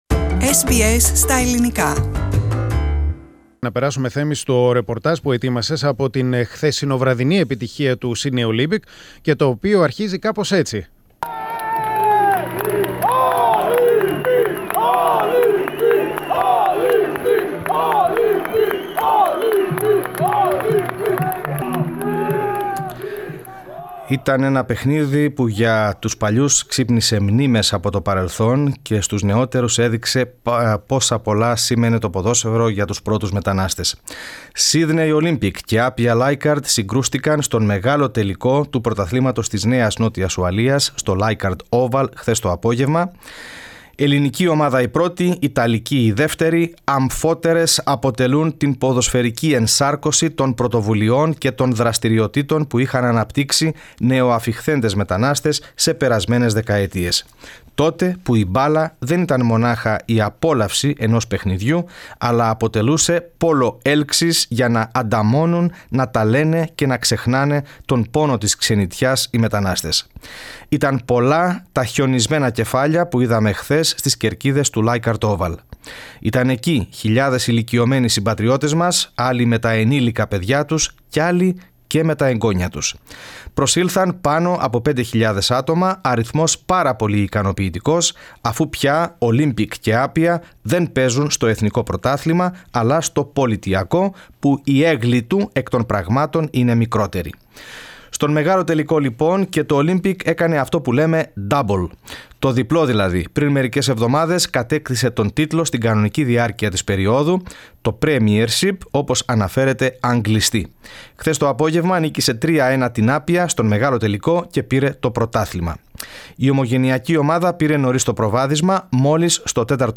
Μετά το παιχνίδι, το πρόγραμμά μας βρέθηκε στον αγωνιστικό χώρο